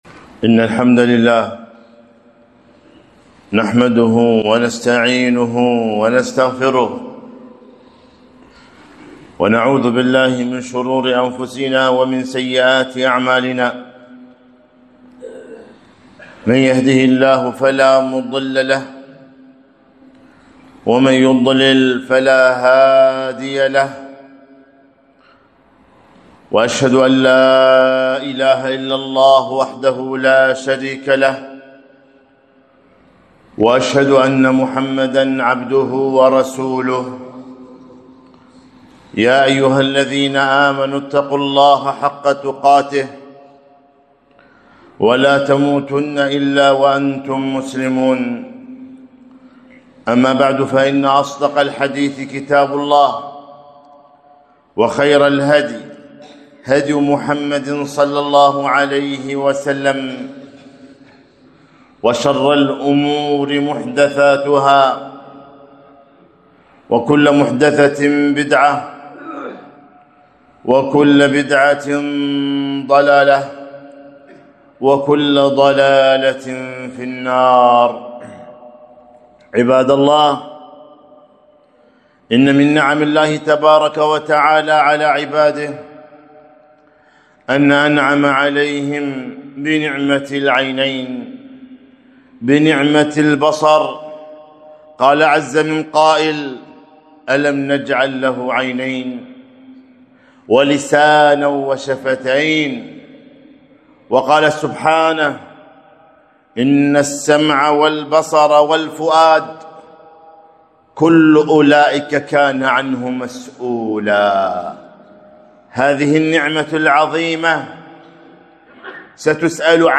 خطبة - لا تتبع النظرة النظرة